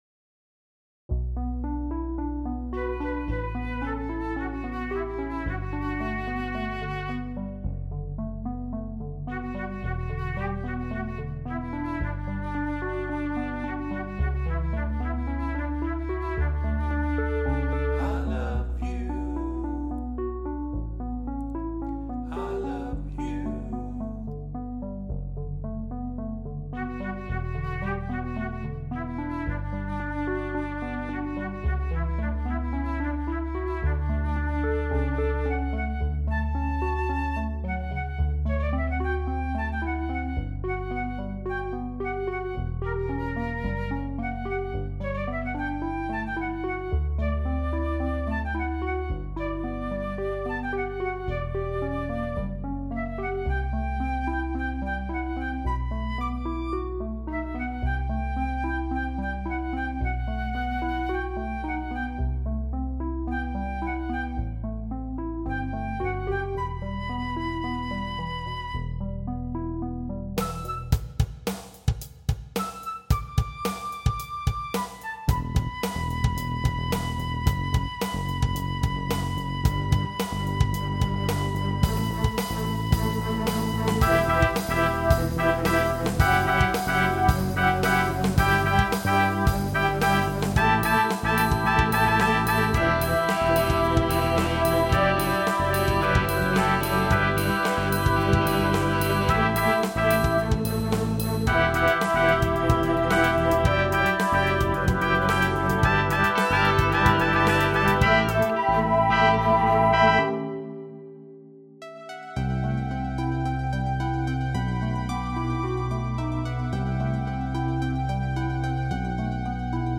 I compose instrumental pieces, mostly for guitar — small, complete forms.
written_and_mixed_song.mp3